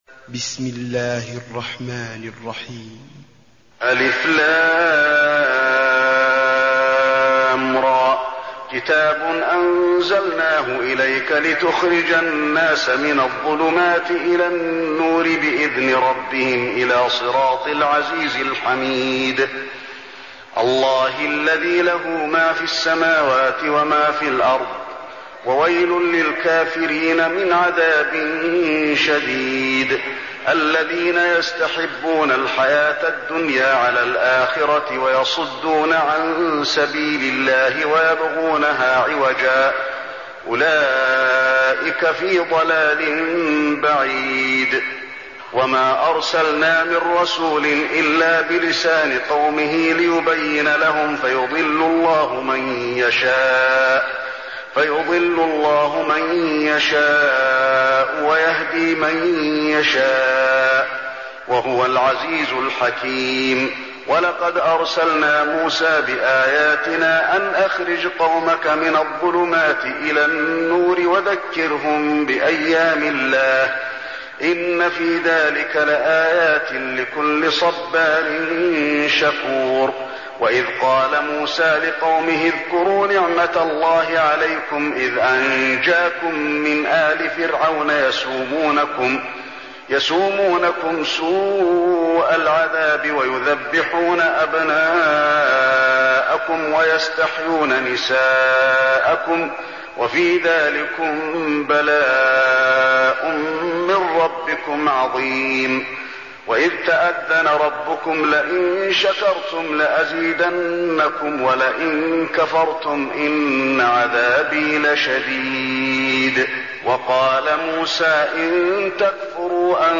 المكان: المسجد النبوي إبراهيم